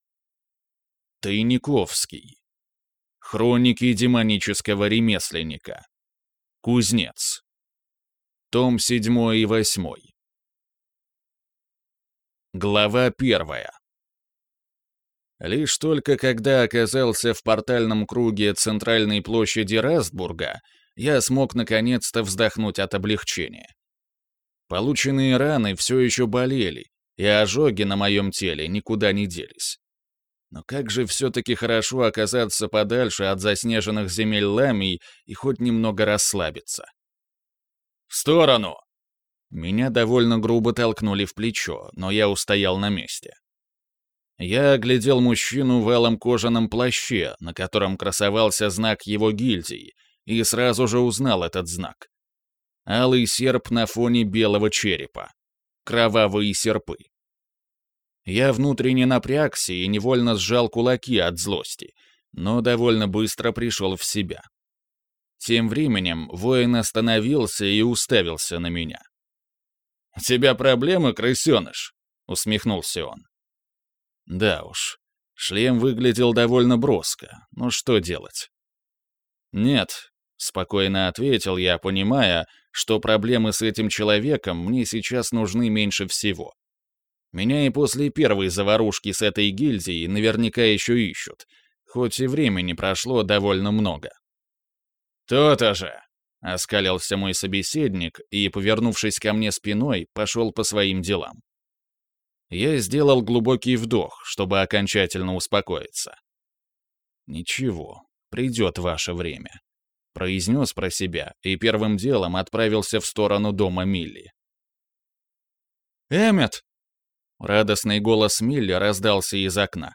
Аудиокнига Хроники демонического ремесленника. Кузнец. Том 7 и 8 | Библиотека аудиокниг